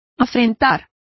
Complete with pronunciation of the translation of affronted.